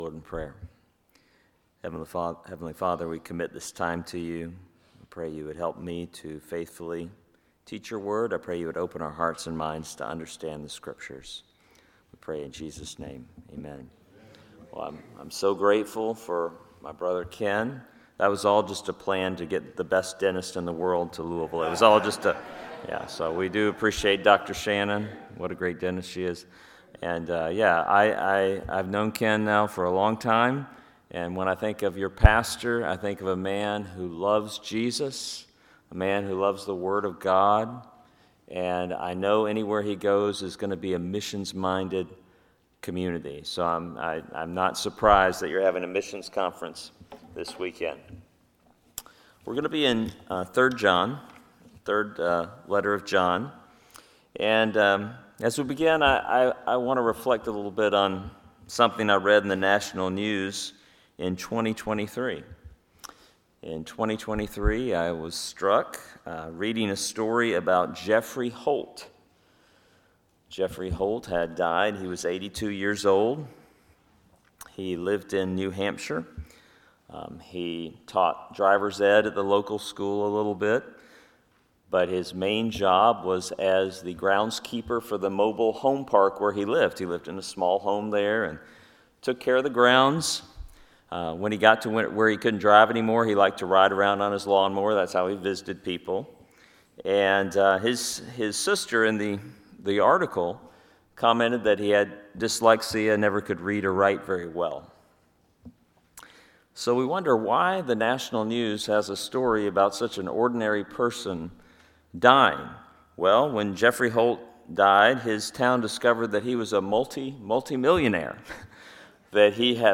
3 John 1-15 Service Type: Sunday AM Topics